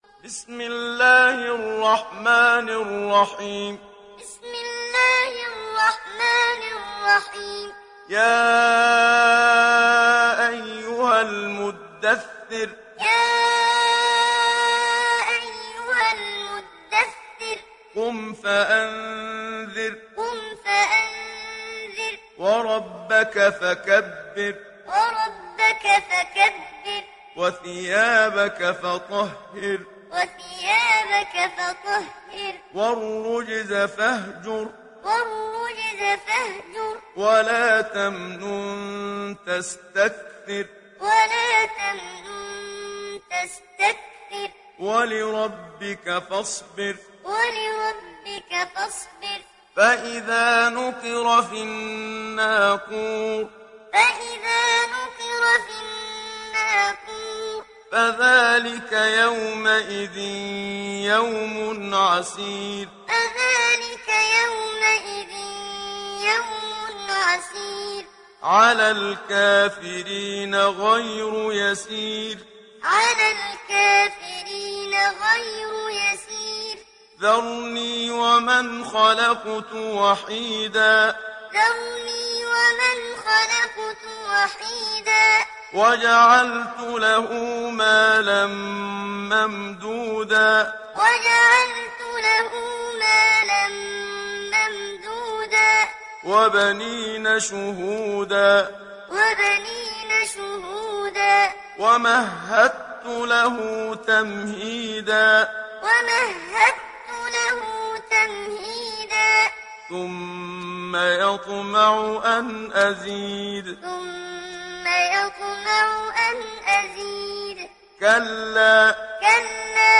Surat Al Muddathir mp3 Download Muhammad Siddiq Minshawi Muallim (Riwayat Hafs)